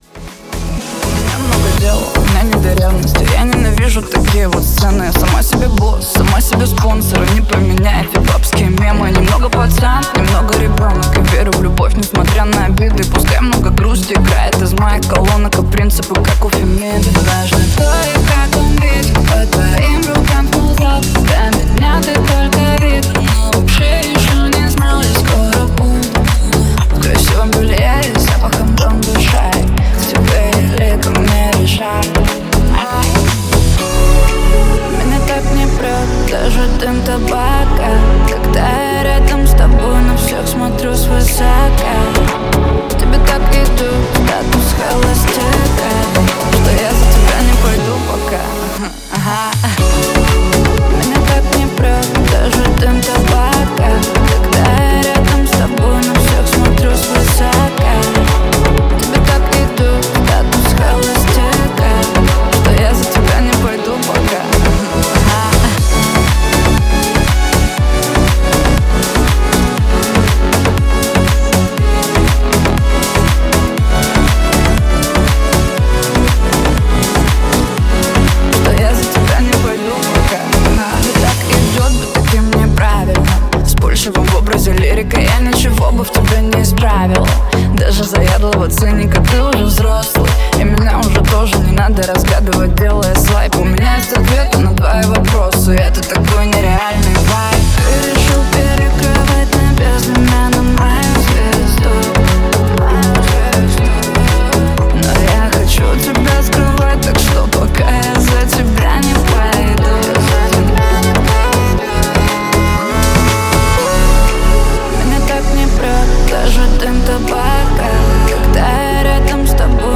Трек размещён в разделе Клубная музыка | Ремиксы.